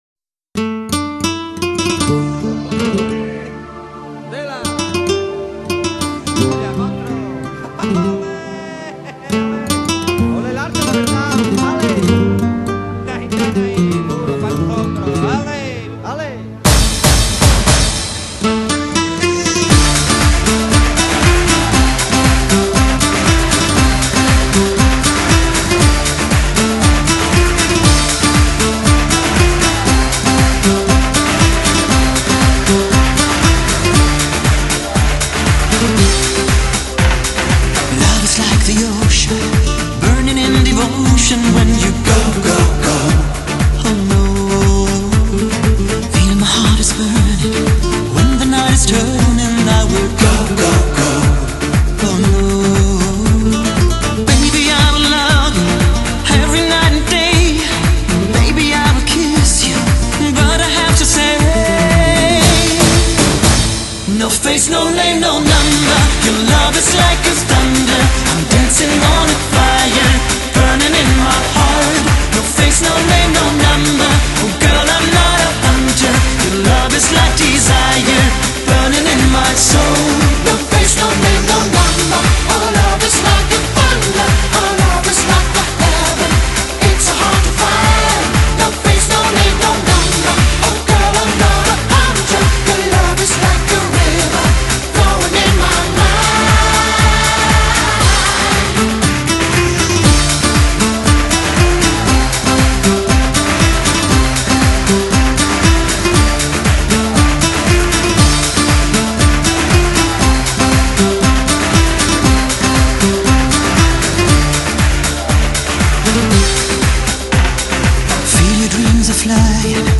Genre: Dance / Soul / Easy Listening ...
Samba